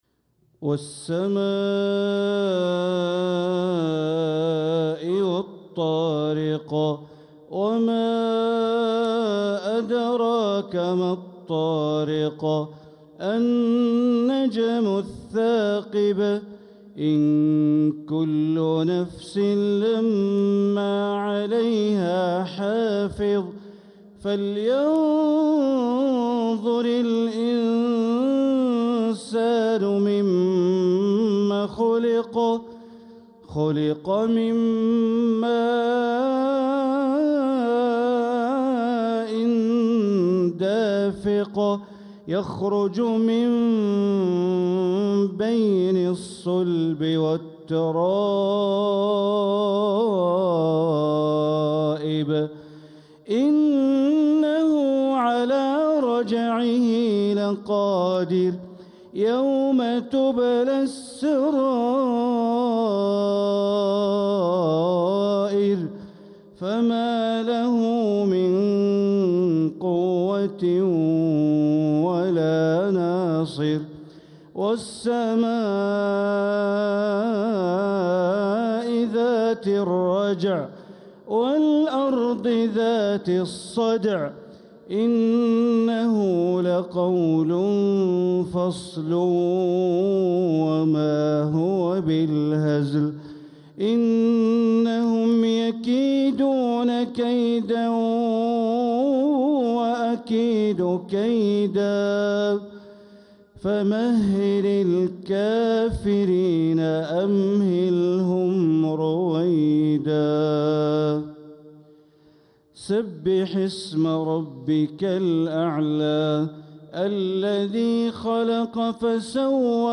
Beautiful recitation by Shiekh Bandar sound effects free download
Beautiful recitation by Shiekh Bandar Baleela in the Haram in Makkah of Surah At-Taariq and Surah Al-A’laa